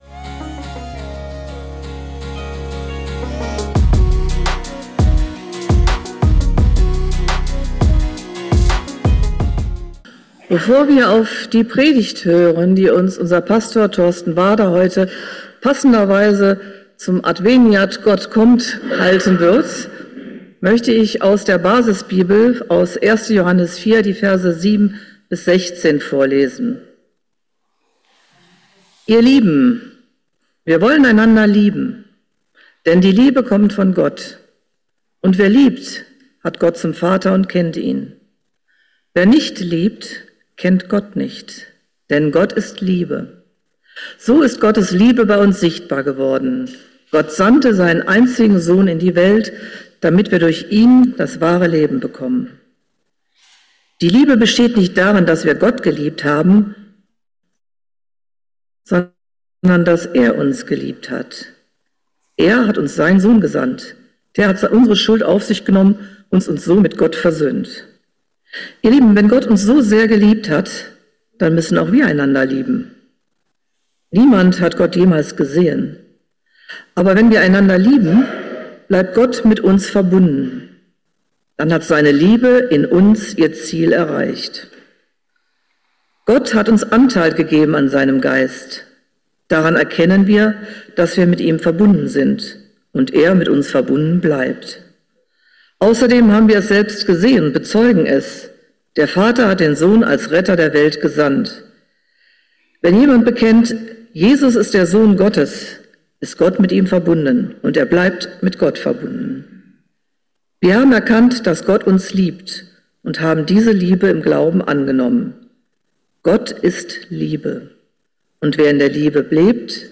Christen müssen der Gesellschaft peinlich sein ~ Geistliche Inputs, Andachten, Predigten Podcast